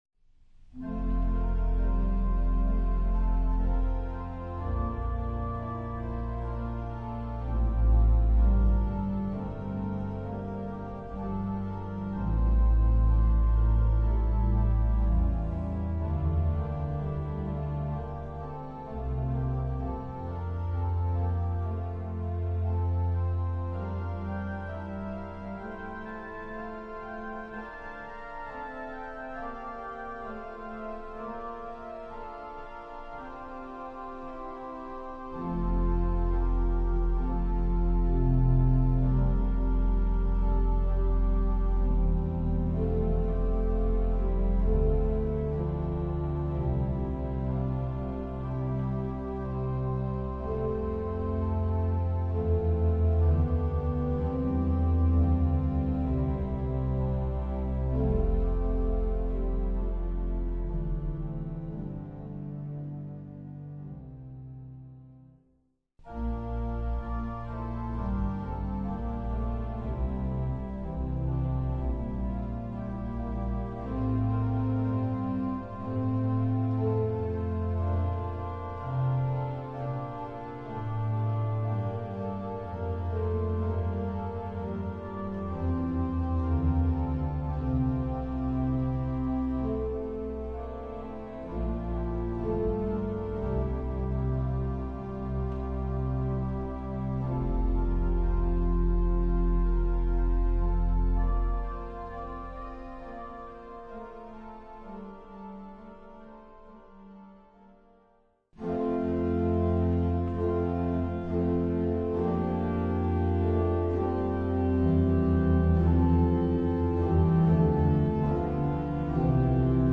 Voicing: Organ Solo